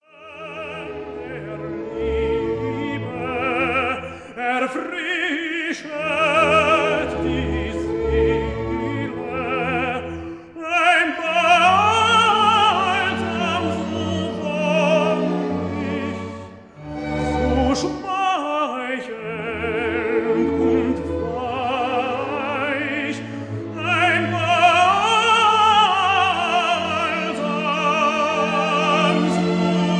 tenor
Stereo recording made in Berlin June 1960